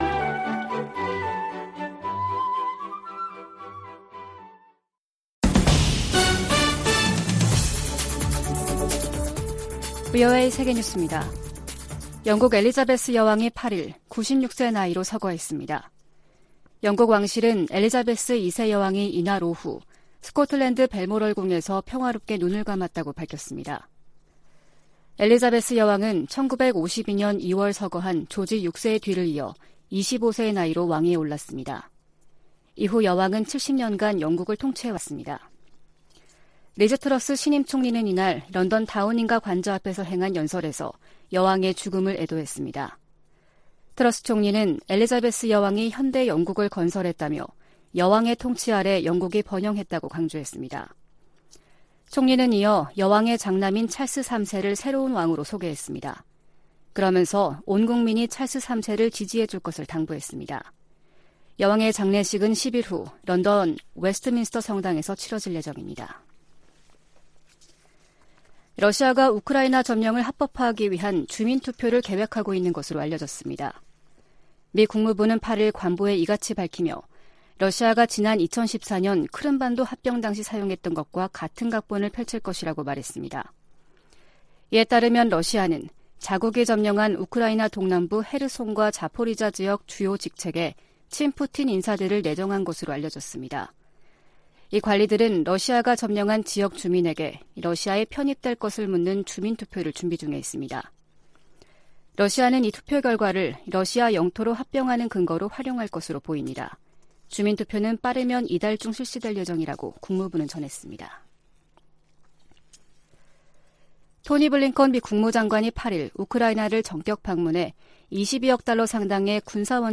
VOA 한국어 아침 뉴스 프로그램 '워싱턴 뉴스 광장' 2022년 9월 9일 방송입니다. 카멀라 해리스 미국 부통령이 오는 25일부터 29일까지 일본과 한국을 방문할 계획이라고 백악관이 밝혔습니다. 한국 외교부와 국방부는 제3차 미한 외교·국방 2+2 확장억제전략협의체(EDSCG) 회의가 오는 16일 워싱턴에서 열린다고 밝혔습니다. 한국 정부가 북한에 이산가족 문제 해결을 위한 당국간 회담을 공식 제안했습니다.